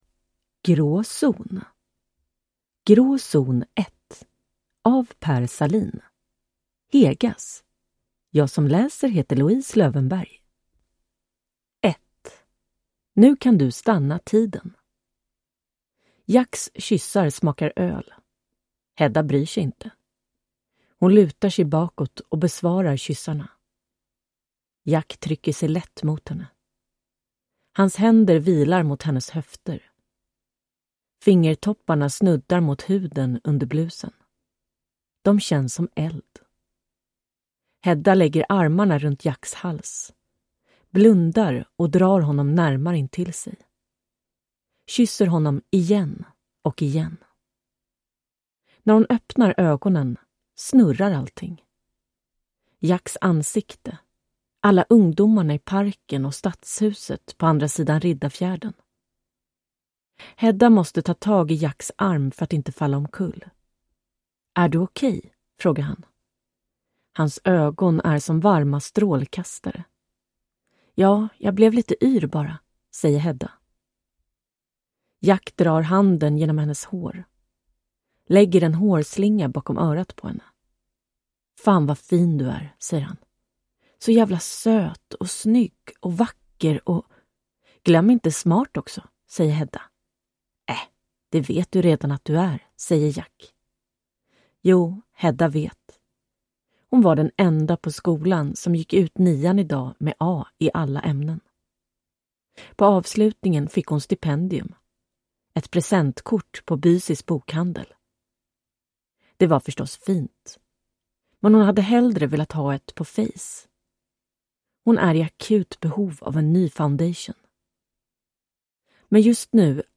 Grå zon – Ljudbok